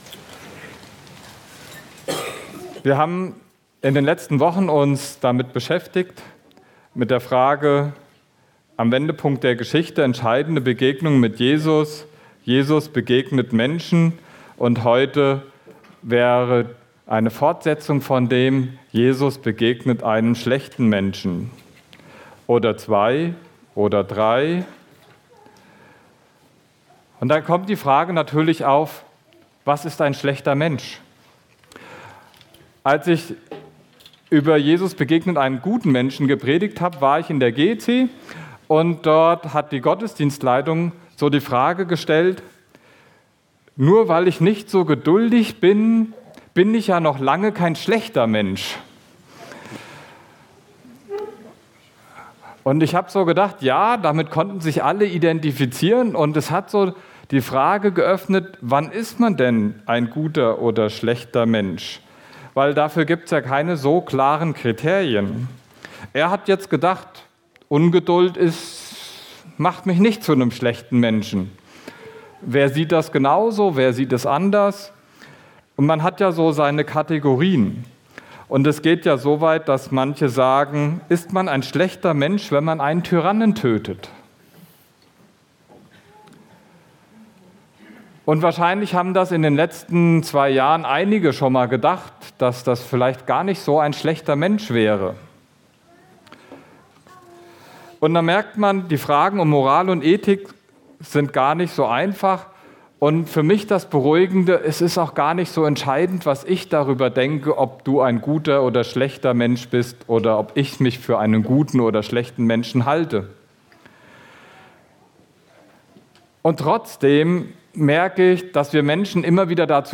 Hier findest du unsere Predigten vom Sonntag zum Nachhören.